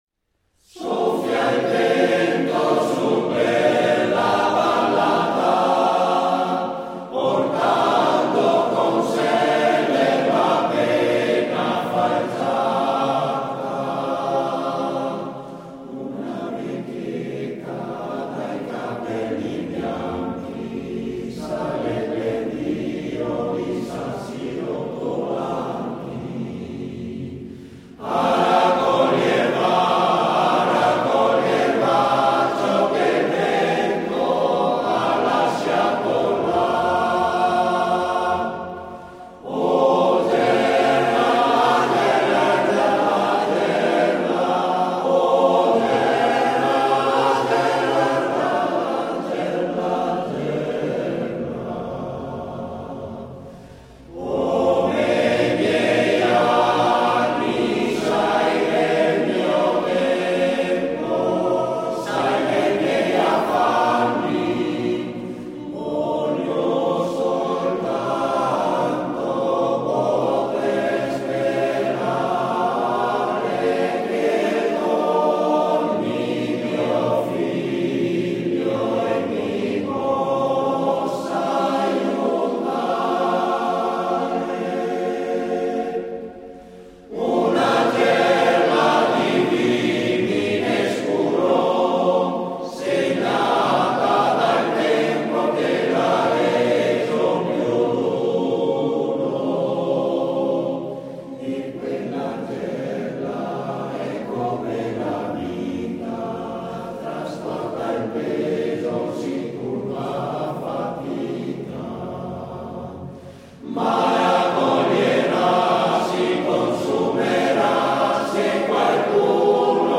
Coro LA GERLA di Spinea